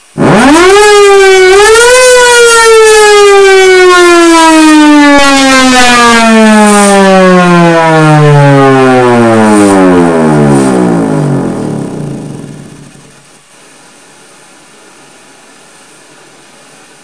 Nuclear Air Raid Siren